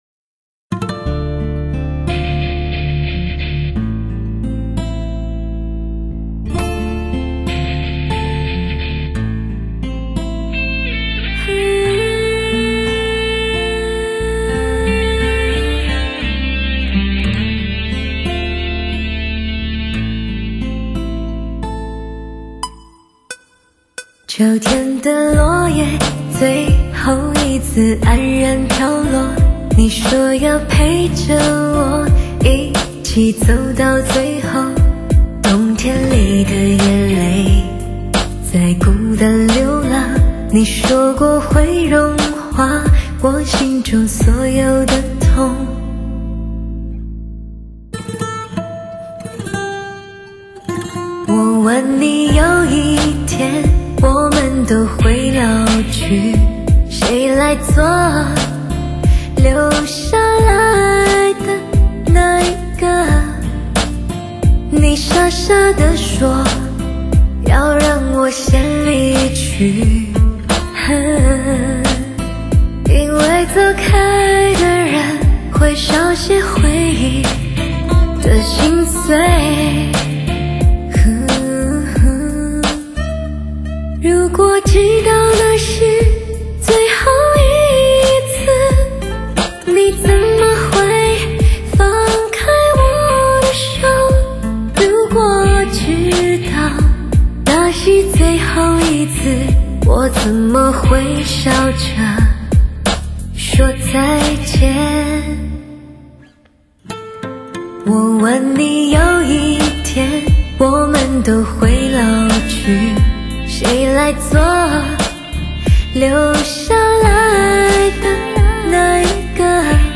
深情代表作 首席疗伤音乐女声魅力
最真实的声音 无可挑剔 最深情的感动